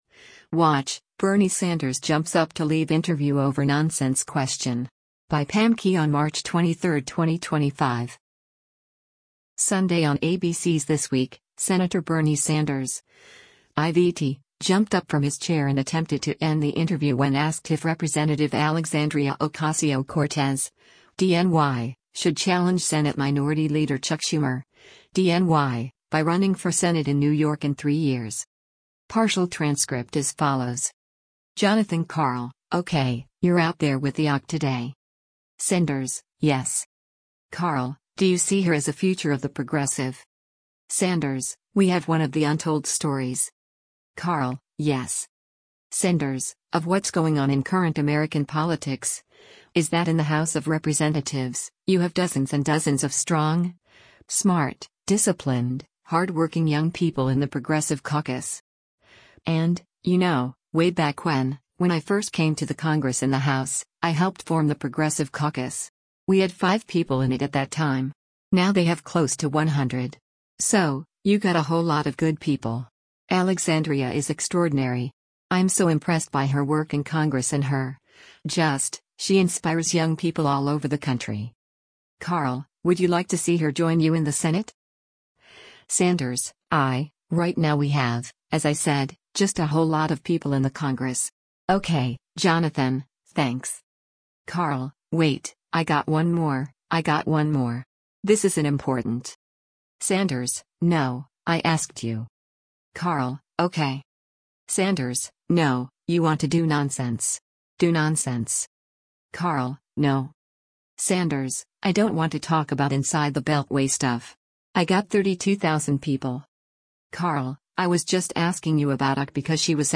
Watch: Bernie Sanders Jumps Up to Leave Interview over 'Nonsense' Question
Sunday on ABC’s “This Week,” Sen. Bernie Sanders (I-VT) jumped up from his chair and attempted to end the interview when asked if Rep. Alexandria Ocasio-Cortez (D-NY) should challenge Senate Minority Leader Chuck Schumer (D-NY) by running for Senate in New York in three years.